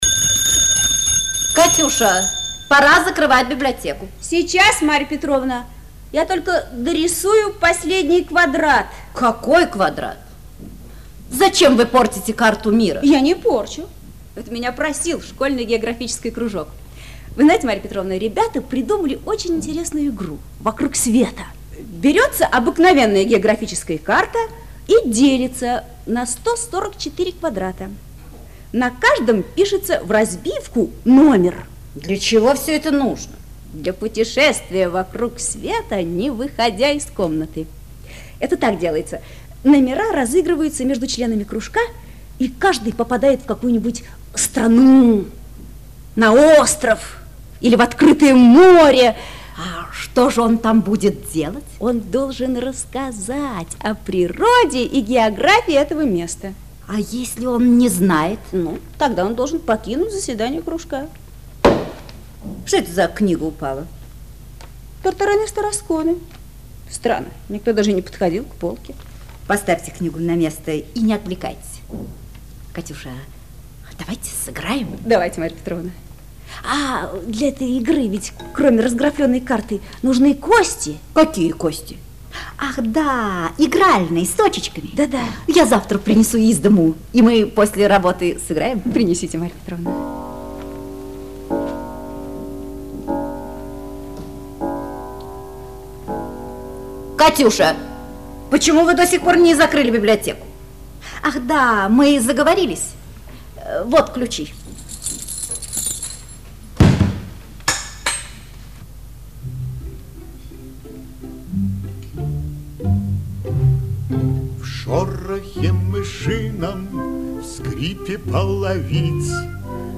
радиоспектакль